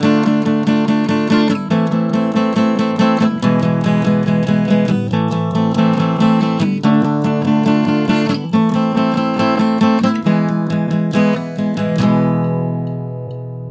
用FL12上的免费声音字体制作的逼真的吉他
描述：逼真但假的吉他循环。
标签： 134 bpm Acoustic Loops Guitar Acoustic Loops 1.21 MB wav Key : A
声道立体声